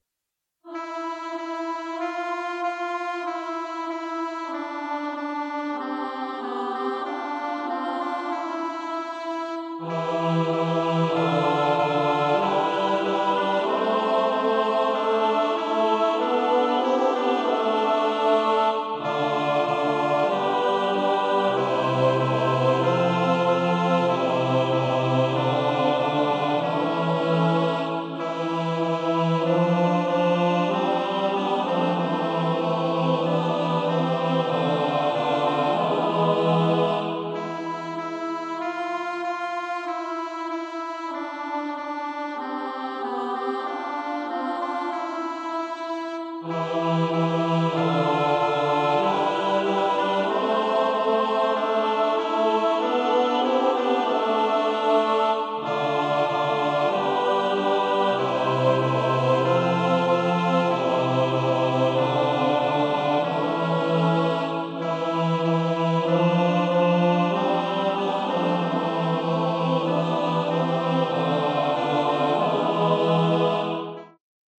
pro smíšený sbor